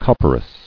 [cop·per·as]